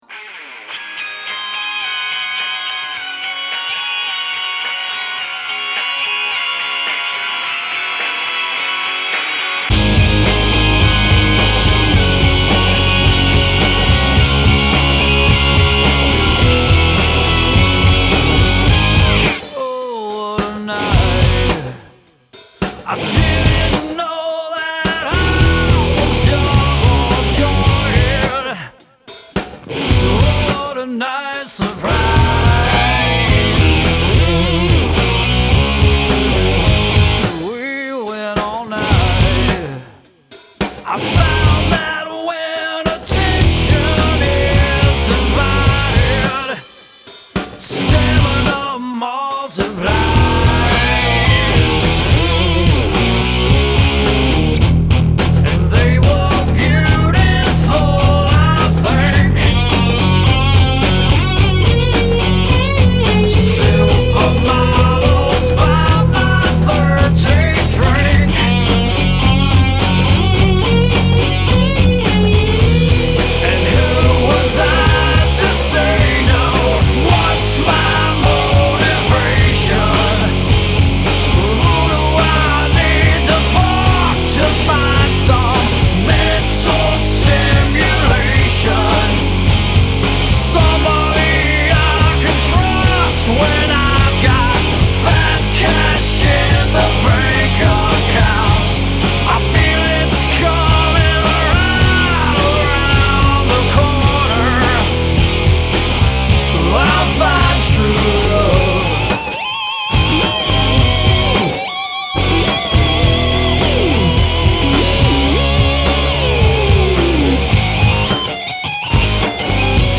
Vocals, Guitars, Bass & Percussion
Drums & Percussion